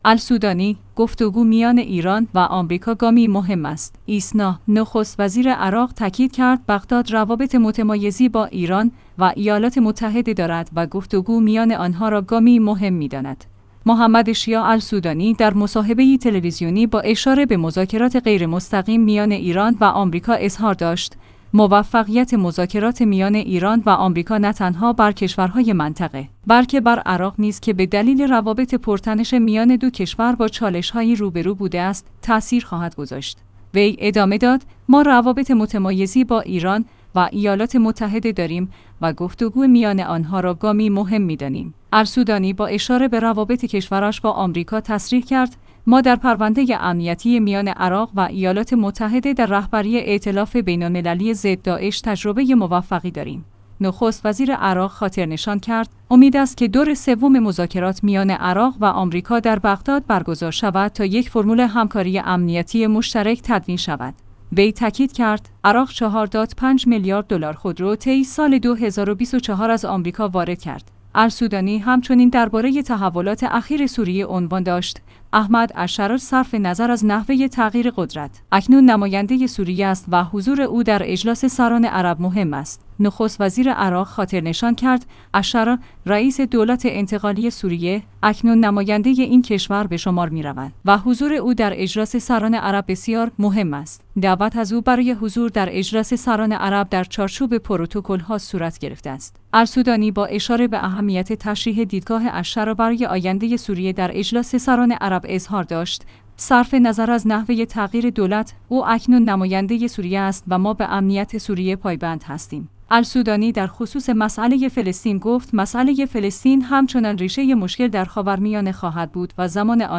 «محمد شیاع السودانی» در مصاحبه‌ای تلویزیونی با اشاره به مذاکرات غیر مستقیم میان ایران و آمریکا اظهار داشت: موفقیت مذاکرات میان ایران و آمریکا نه تنها بر کشورهای منطقه، بلکه بر عر